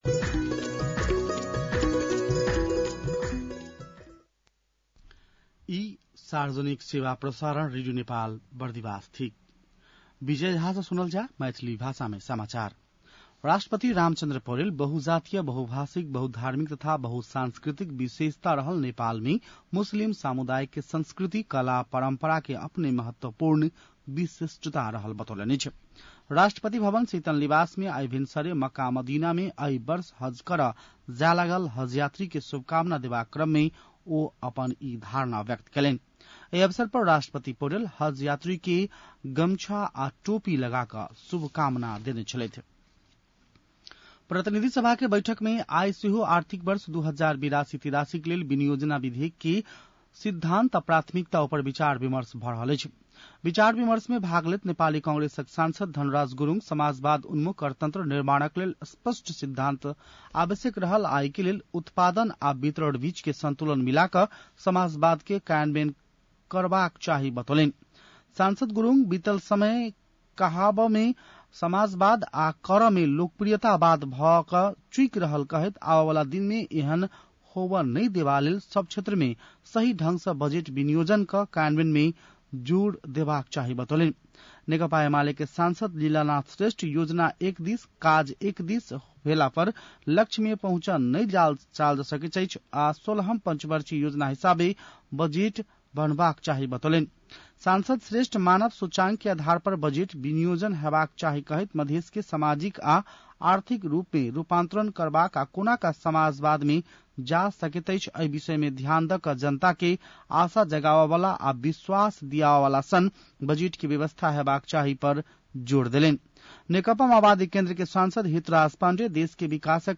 मैथिली भाषामा समाचार : २ जेठ , २०८२
Maithali-news-02-2.mp3